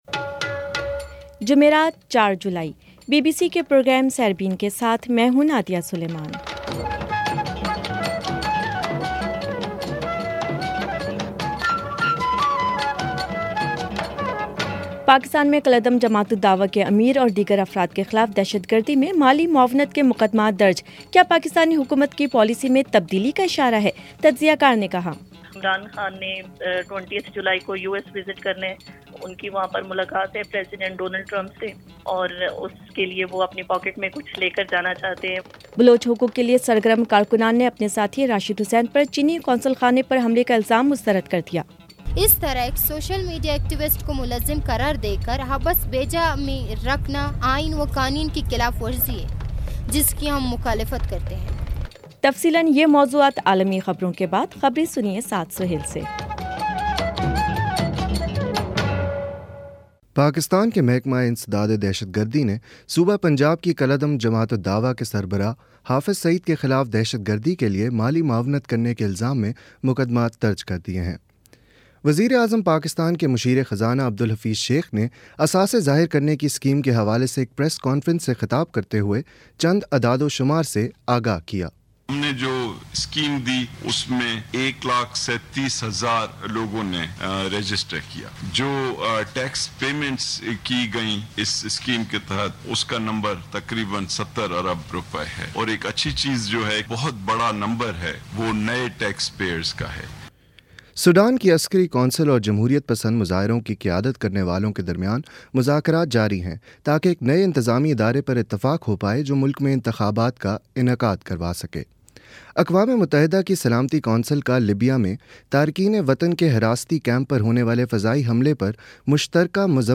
جمعرات 04 جولائی کا سیربین ریڈیو پروگرام